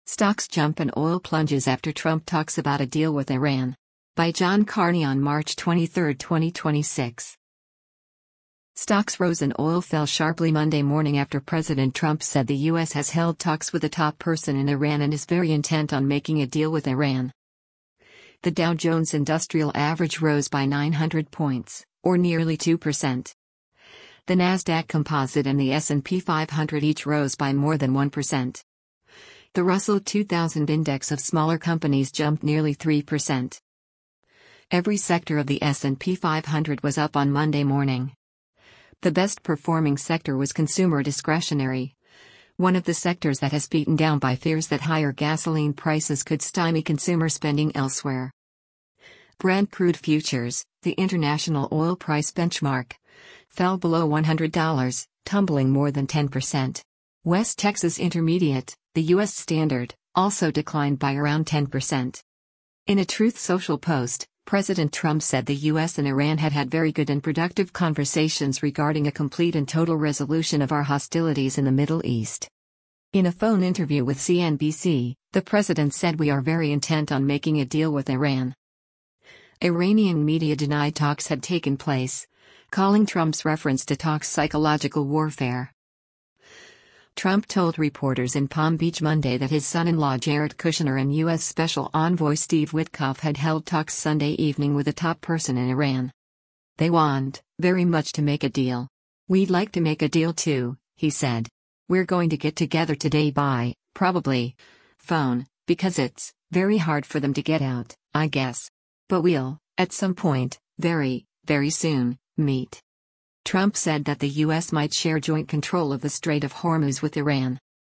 US President Donald Trump speaks to reporters before boarding Air Force One at Palm Beach